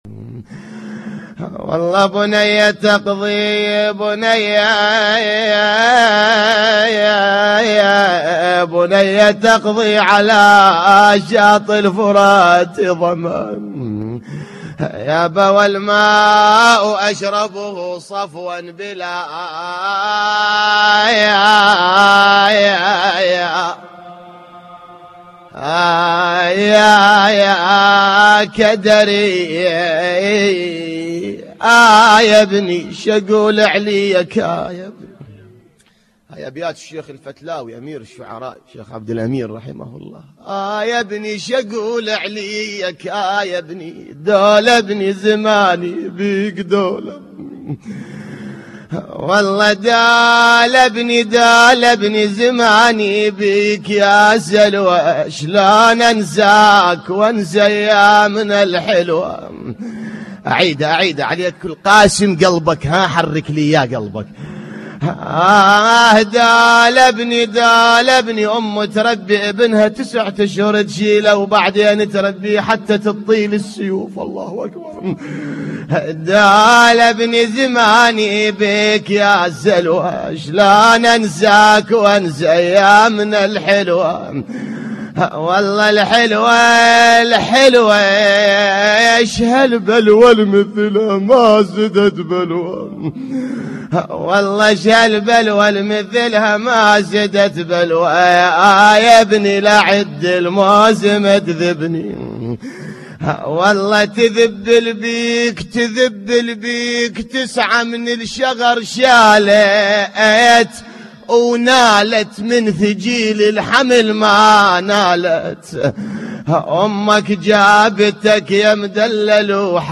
للتحميل This entry was posted in نواعي